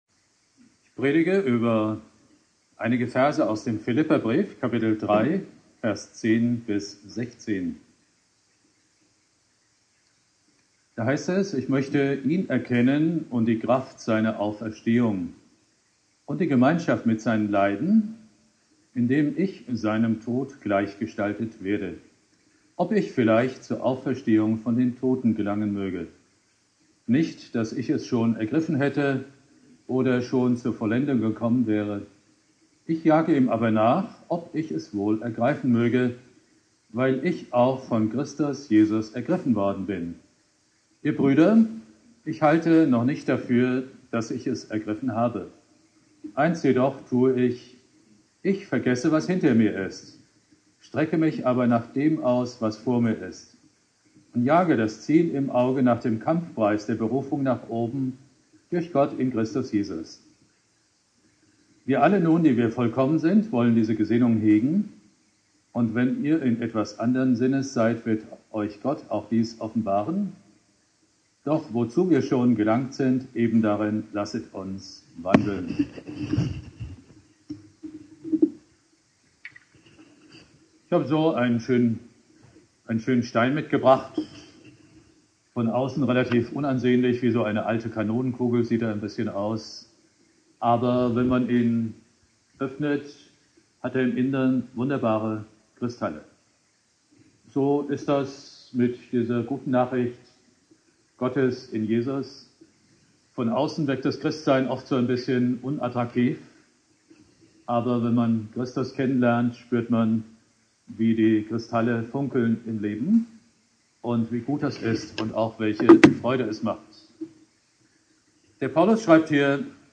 Predigt
Thema: "Von Jesus ergriffen" (im Haus Jona gehalten) Bibeltext